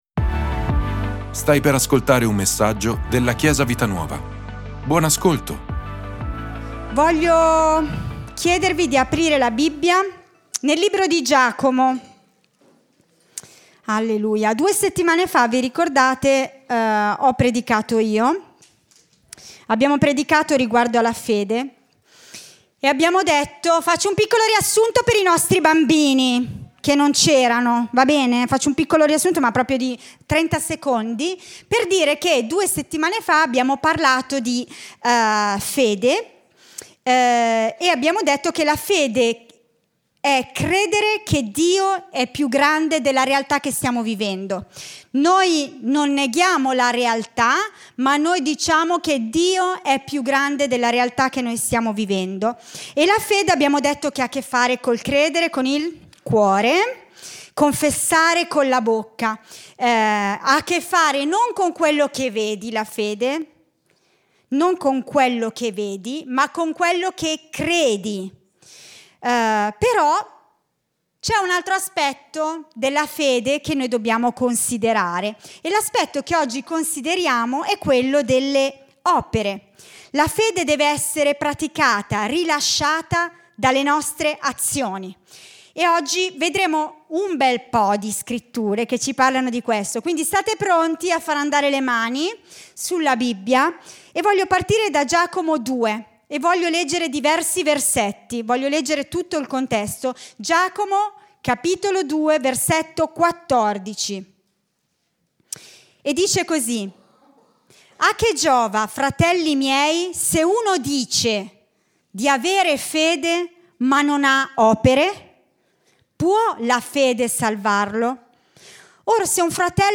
Ascolta la predicazione "08/26_Senza ....è morta " di Chiesa Vita Nuova.